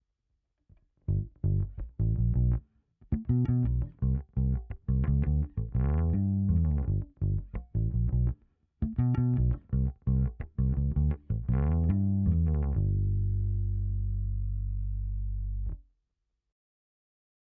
Genau, so in der Art klingt es, allerdings sind es bei mir nicht die Fingernägel.
Um es zu verdeutlichen, ist die Höhenblende komplett offen.